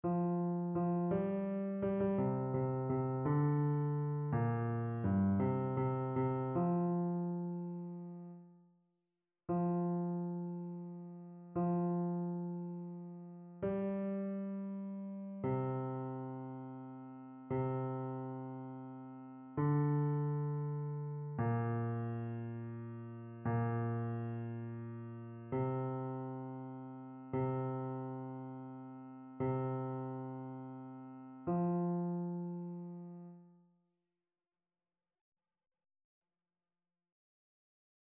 Basse
annee-b-temps-pascal-5e-dimanche-psaume-21-basse.mp3